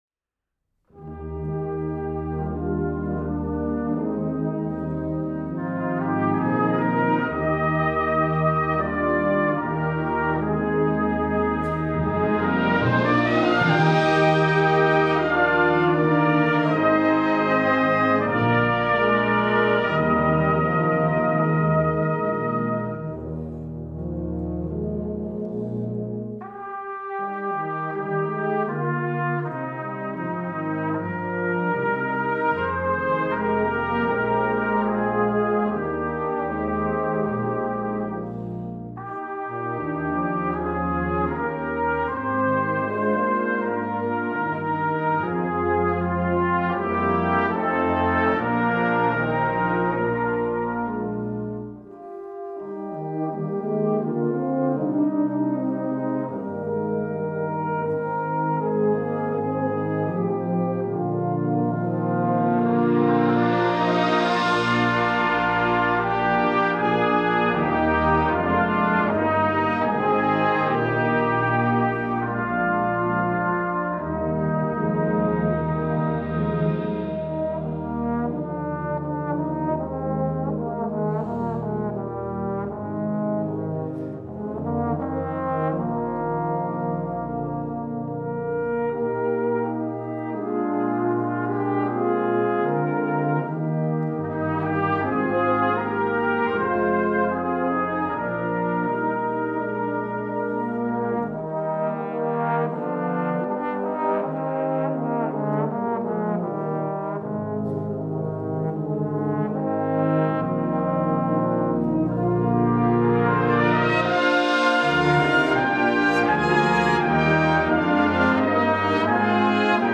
(Hymn Tune Arrangement)